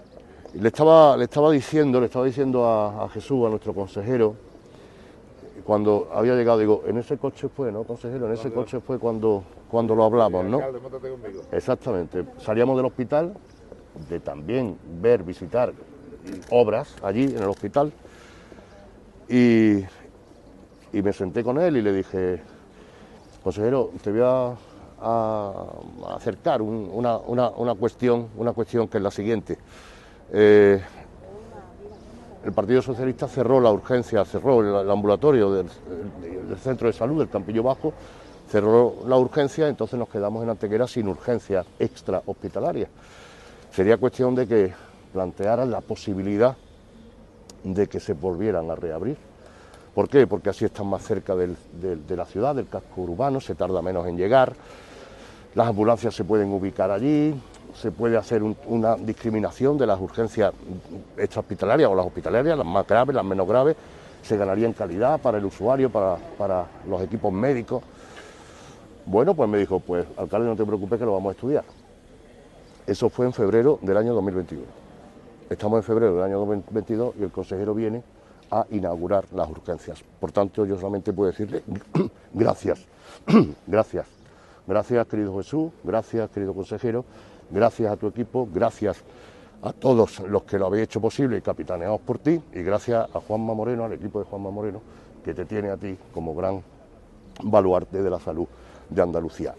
“Esto es apostar por la sanidad pública, abriendo urgencias, dotando de mejores servicios a los ciudadanos, mejorando la atención primaria; lo que no es apostar por la sanidad pública es cerrar centros de salud, como se cerró este del Campillo Bajo y después aparecer detrás de una pancarta en una manifestación completamente política. Obras son amores, y amor al ciudadano, a los usuarios y los que están enfermos teniendo hoy más fácil el acceso a las urgencias extrahospitalarias en Antequera. Esto es apostar por la sanidad pública, lo otros son mentiras, falacias y engaños. El movimiento se demuestra andando y apostar por la sanidad pública se demuestra, por ejemplo, abriendo las urgencias del campillo bajo”, declaraba Manolo Barón ante los medios de comunicación.
Cortes de voz